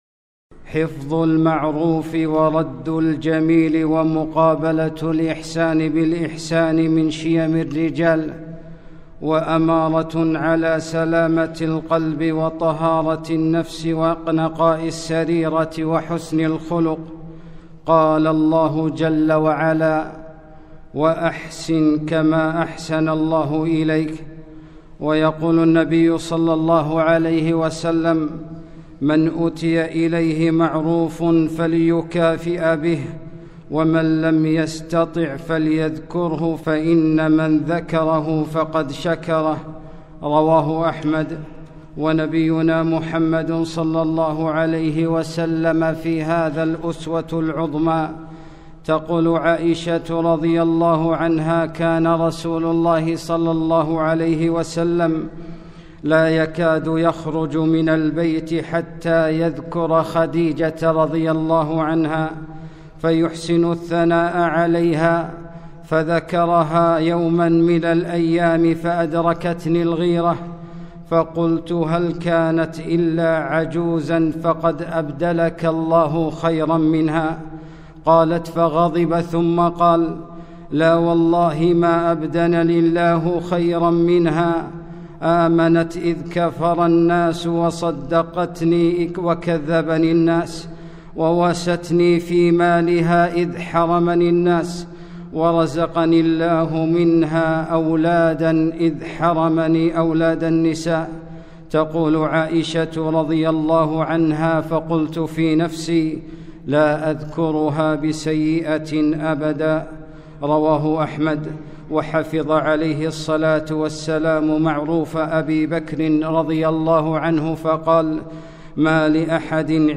خطبة - حفظ المعروف